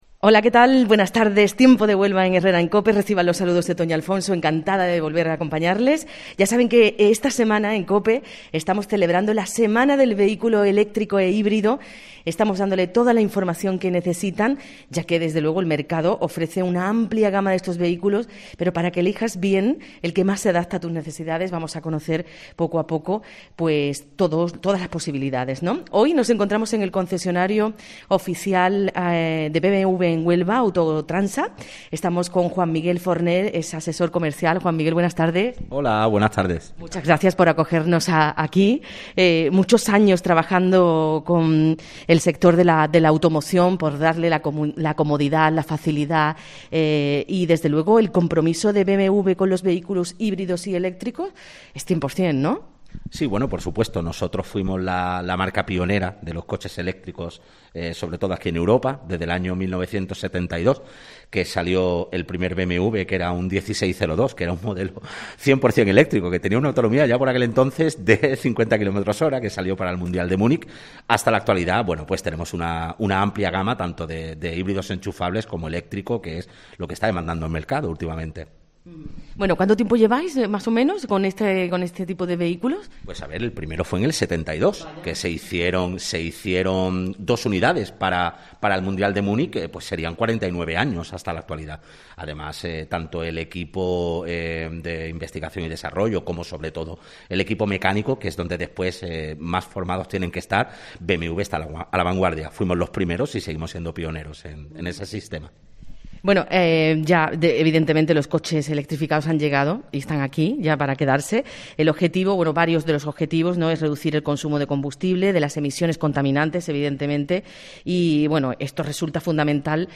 Concluimos la Semana del Vehículo Eléctrico e Híbrido visitando en el tiempo local de Herrera en COPE Autogotransa, concesionario de BMW y Mini para la provincia de Huelva.